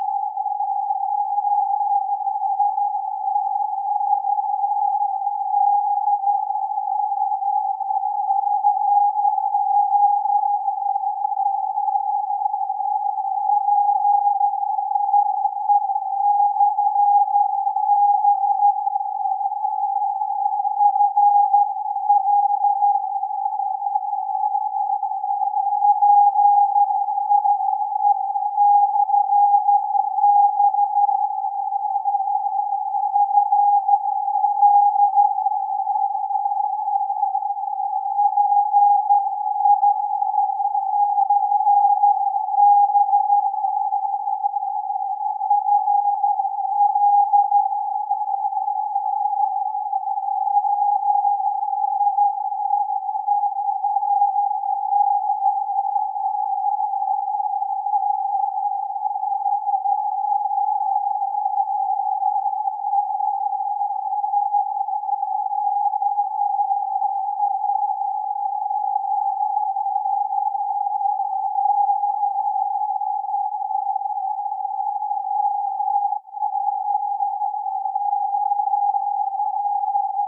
Here are clips from the first and the latest audible instances of "PIE/B FL" as copied in SE Kansas.
The June 7 Argo capture illustrates the approximately 2-3 minute QSB cycle that is common to 22 meters at certain times, while the June 19 MP3 is afflicted with the 3-6 second rapid-fading cycle that chopped up the ID into fragments that read like "/B" then "PI /B" and "L" or "FL" and so on.
Receive setup is a Kenwood TS-590 with TXCO reference, fed from the 40-foot top hatted vertical antenna without the usual buffer amp ahead of the coax.